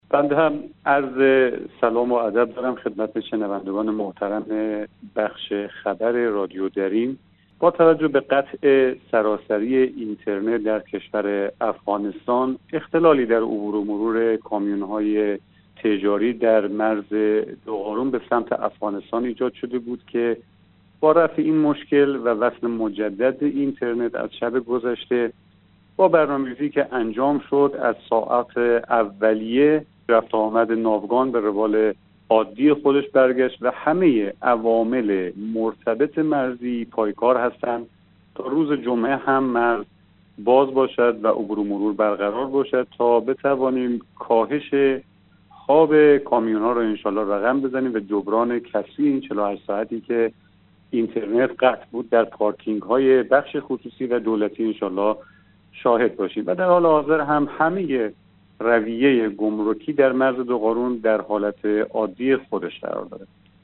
مهدی رجبی معاون سیاسی، امنیتی و اجتماعی فرماندار شهرستان تایباد ایران در گفت‌وگو با رادیو دری تصریح کرد که با وصل شدن دوباره اینترنت در افغانستان مشکل خروج ناوگان تجاری از ایران به سمت افغانستان برطرف شده است.